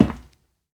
StepMetal1.ogg